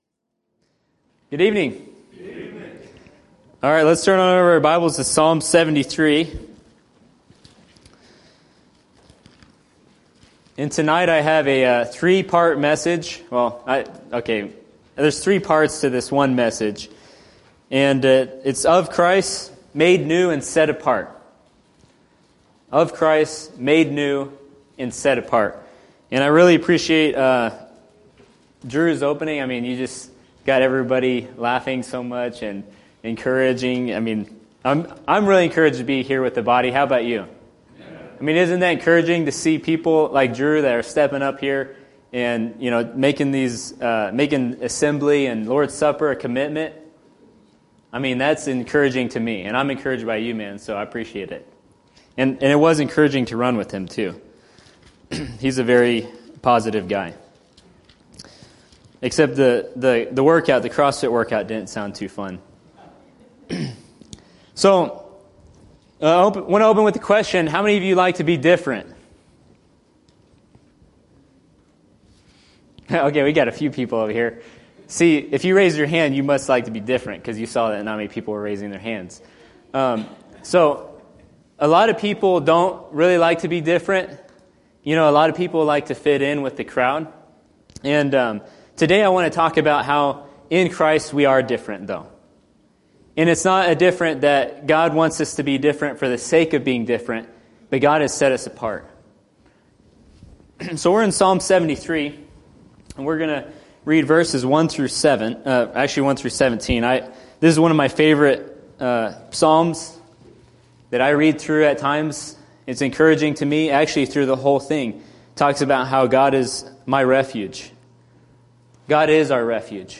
Series: Evening Messages Tagged with different , corrupted world , reconciled , sanctified , Peter , set apart , made new , Satan , sheep , cooperation , Of Christ , Jesus